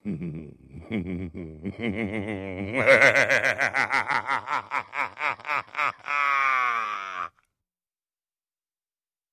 Laughter
Deep evil laugh, male